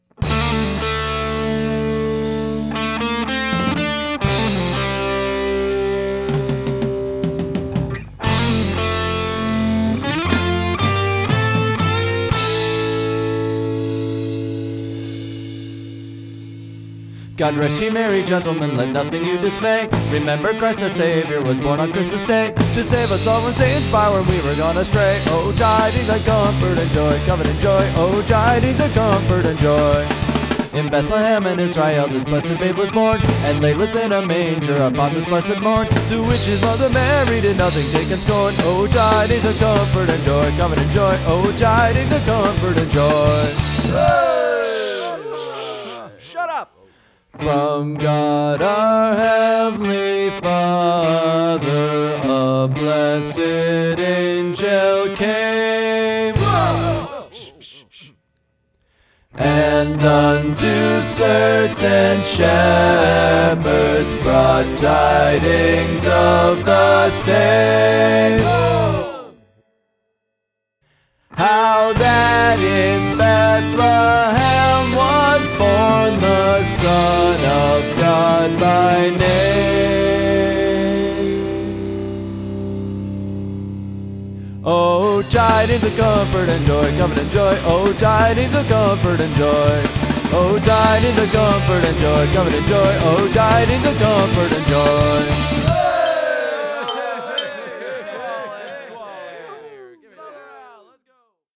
It’s another year, so here’s another four tracks of holiday music for you to rock out to… because a) it’s more fun this way and b) way too much Christmas music takes itself way too seriously.
My usual Christmas hallmarks/traditions/tricks are all here in full force as well, including the “Holy crap!” sound file.